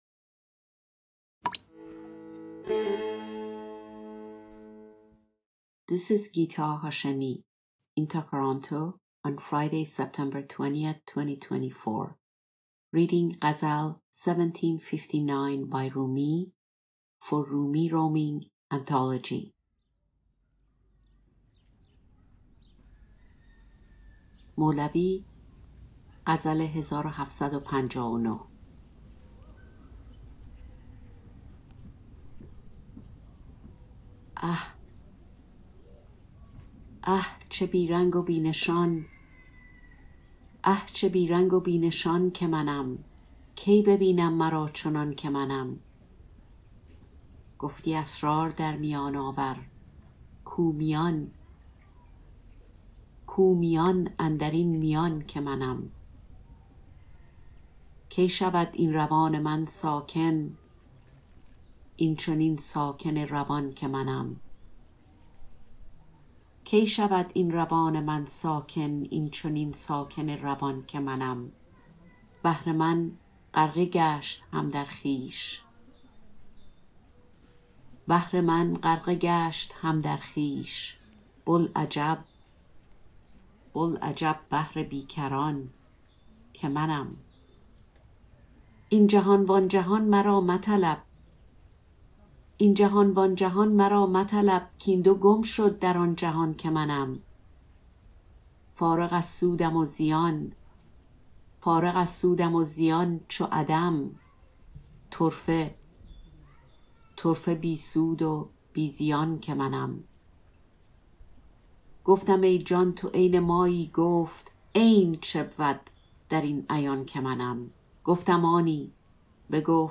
Rumi, Ghazal 1759, Translation, Rumi roaming, Poetry, Self and identity